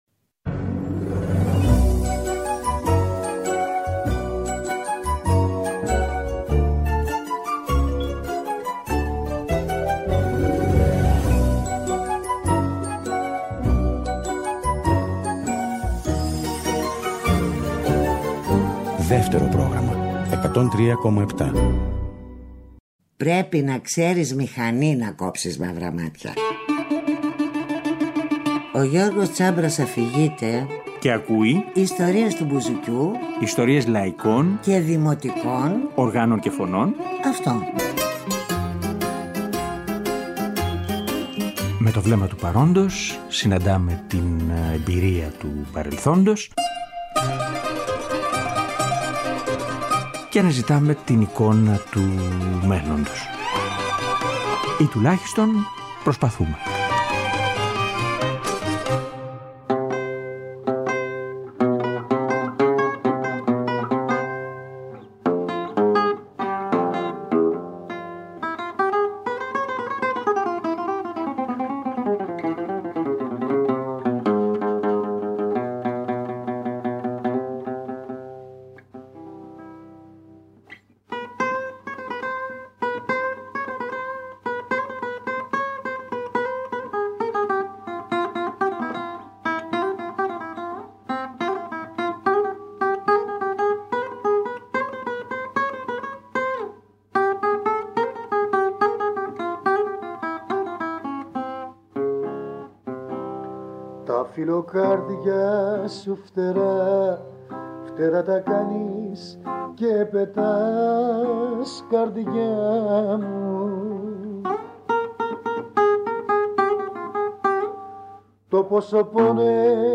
Και συζητάμε…
Από αυτό ακούμε και χαρακτηριστικά δείγματα…